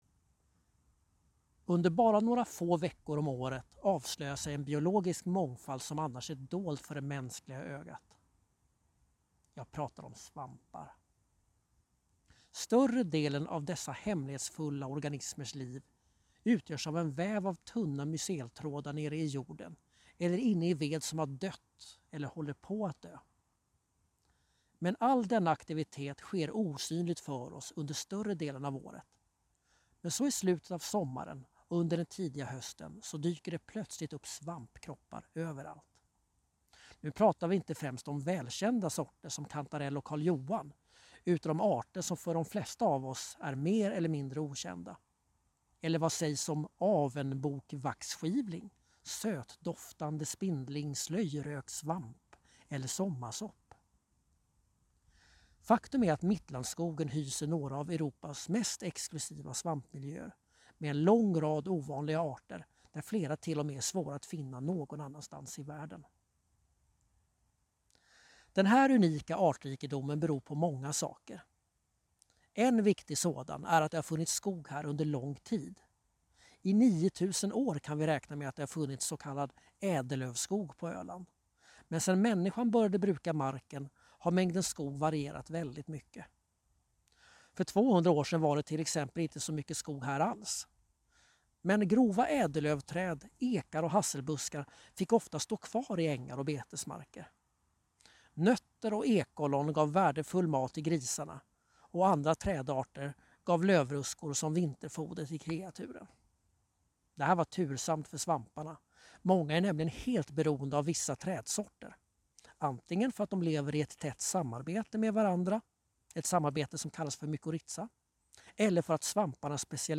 En vandring genom Mittlandet tar dig genom unika naturmiljöer och rik kulturhistorik. Lyssna på en berättelse om Mittlandet här!